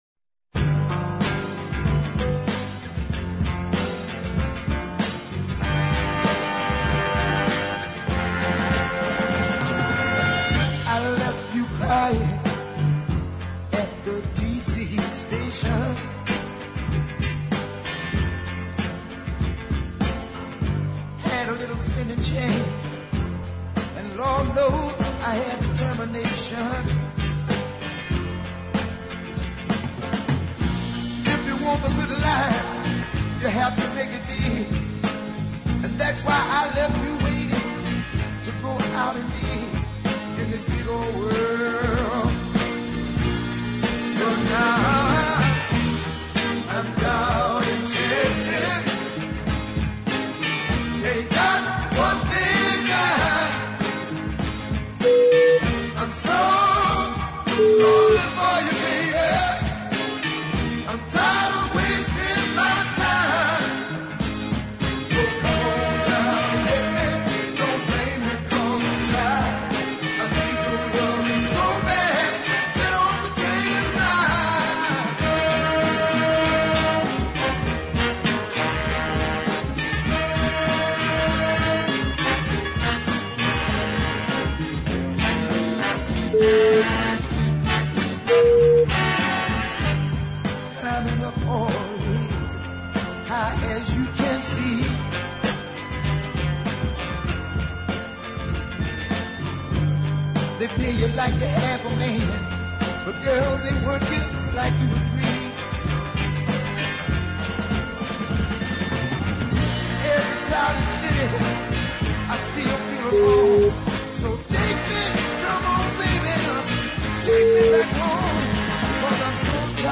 bass lines